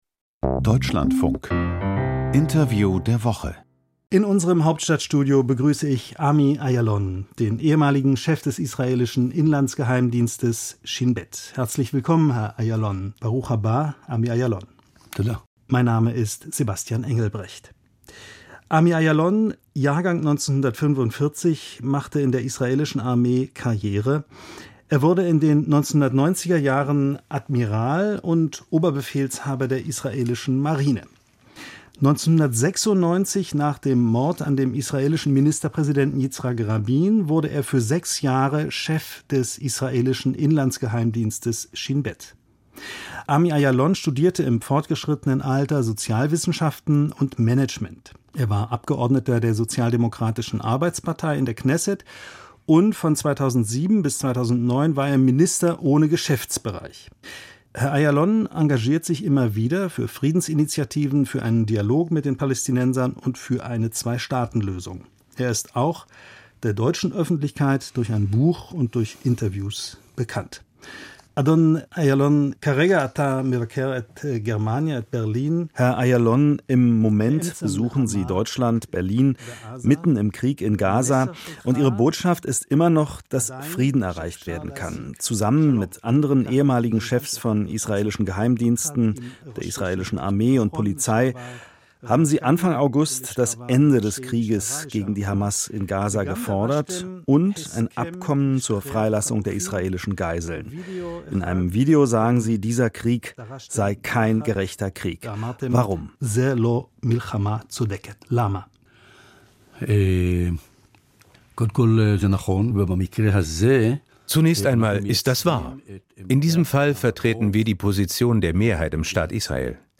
Politik und Gesellschaft - Interview der Woche
Entscheider aus Politik, Wirtschaft und Sport stehen 25 Minuten lang Rede und Antwort.